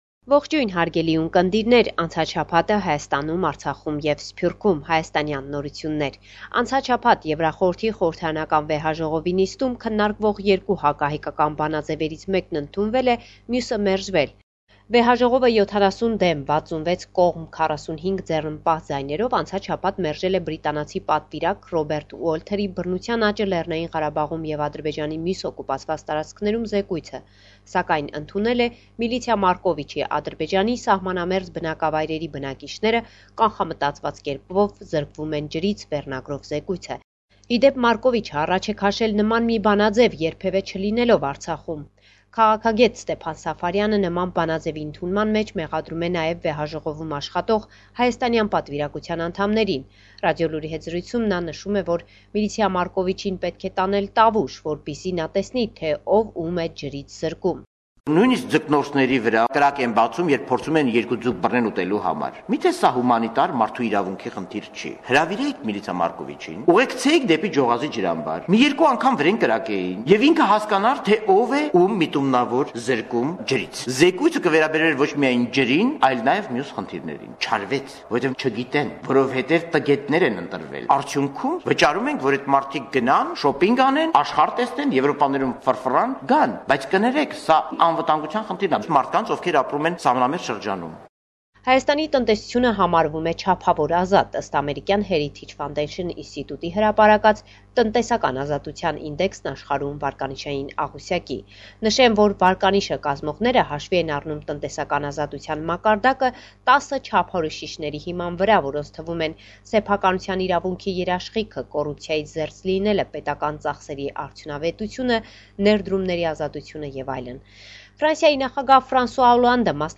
LATEST NEWS – 2 February 2015